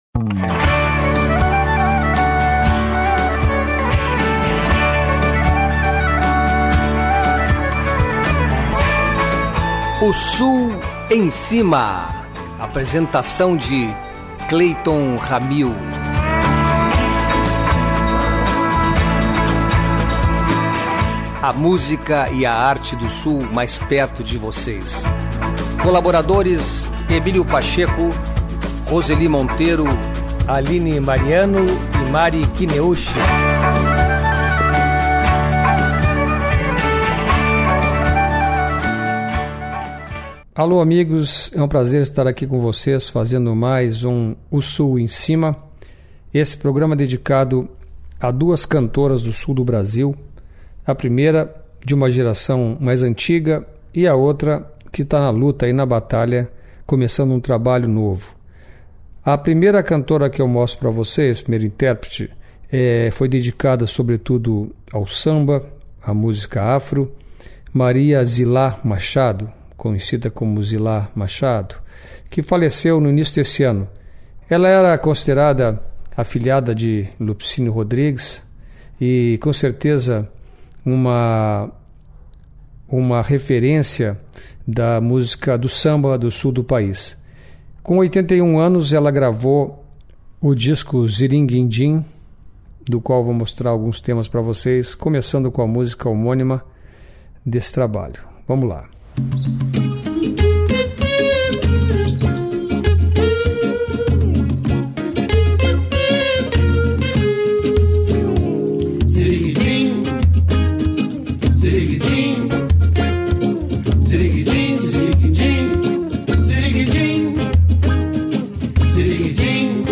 Duas gerações de cantoras de estilos diferentes.